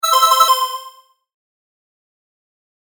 正解の音・ピンポーンだけを集めた、フリー素材です！
▶正解９５（昭和のクイズ番組の正解音風。）【DL】
correct095.mp3